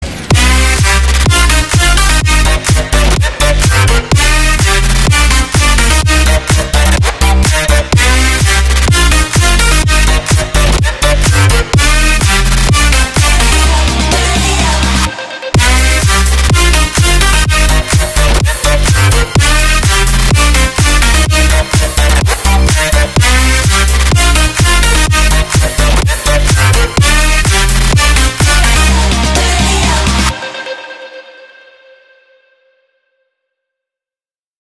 громкие
EDM
мощные басы
энергичные
Стиль: future house